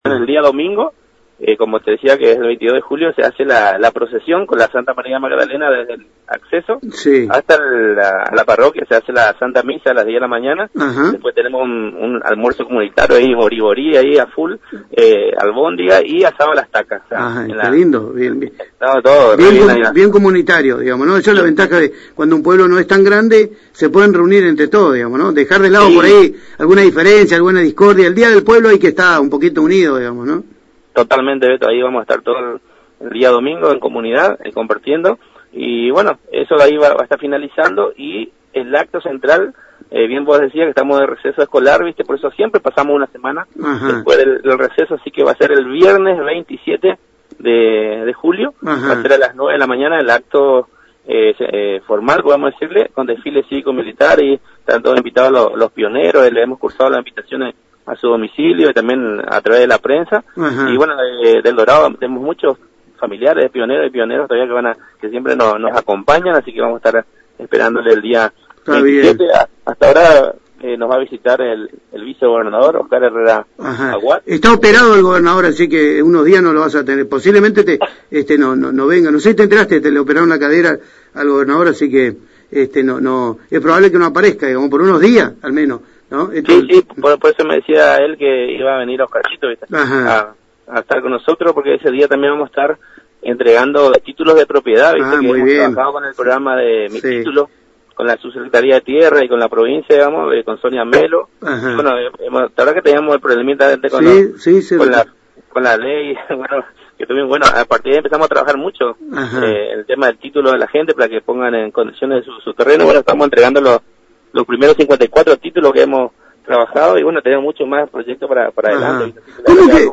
En diálogo con ANG y Radio Génesis el jefe comunal contó que el desfile cívico militar y reconocimiento a los pioneros, se realizará el viernes 27 de julio a partir de las 9 de la mañana en el acceso al pueblo con la presencia del vicegobernador de la provincia de Misiones, Oscar Herrera Ahuad, funcionarios provinciales y locales. Además, el intendente Eberth Vera, afirmó que en la ocasión se hará entrega de los primeros 54 títulos de propiedad del programa provincial “Mi título” junto a referentes de la subsecretaria de Tierras y de la provincia.